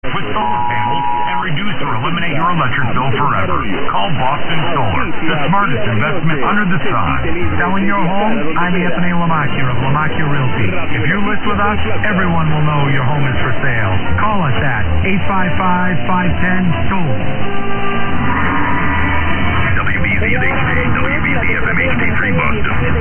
WBZ versus Argentina on 1030, heard 21 APR 2016 at 0100 UTC at South Yarmouth, MA.
Receiver: Perseus
Antenna: 10 m vertical by 11 m horizontal cardioid-pattern SuperLoop, base height 1.5 m, null 345 deg. / peak 165 deg.